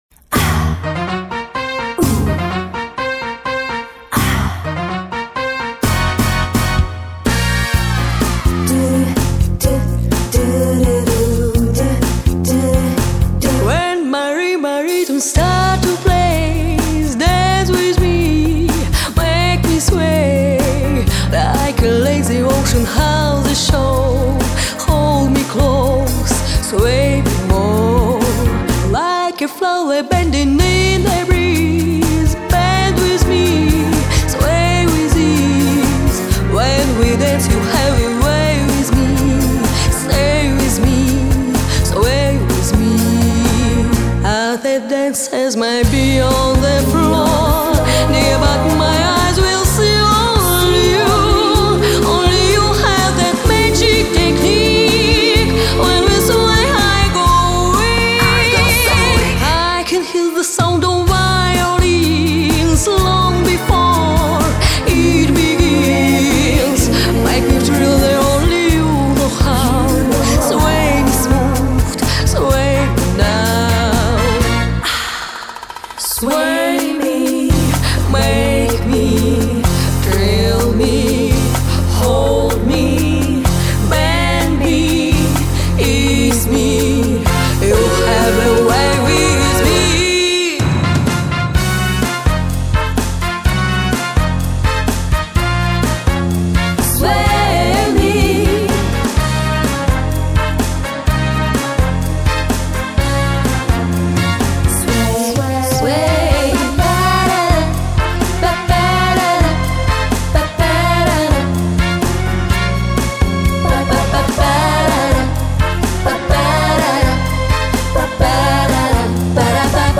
Обволакивающий и летящий голос!